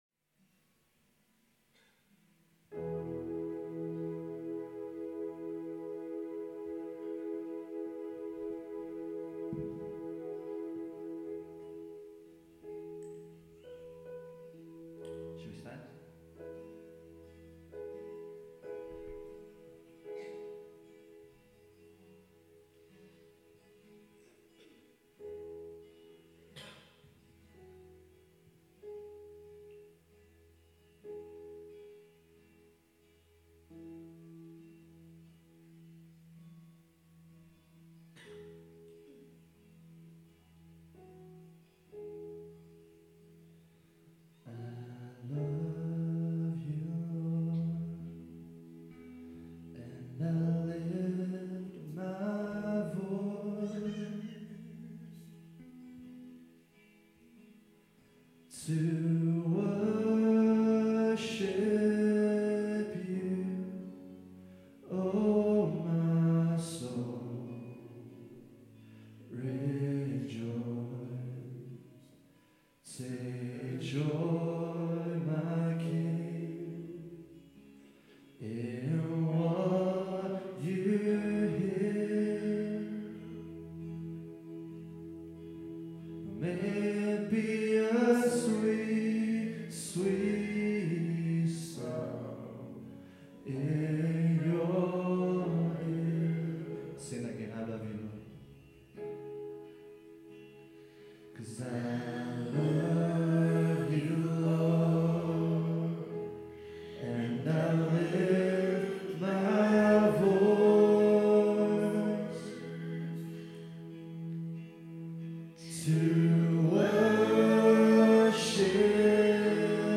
Worship May 10, 2015 – Birmingham Chinese Evangelical Church
Lead/Guitar
Vocals
Keys
Drums
Bass